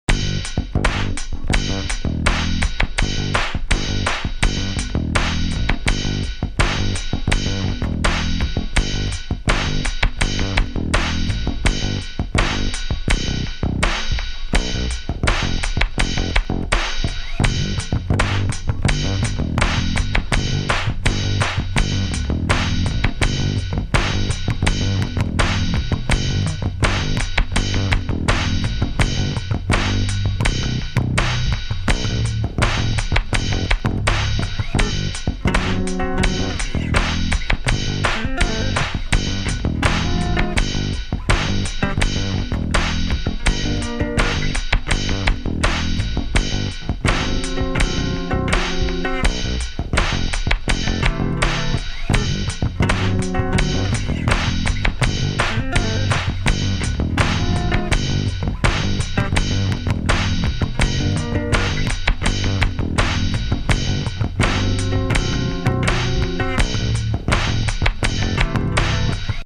home of the daily improvised booty and machines -
orginal 3 bass grooves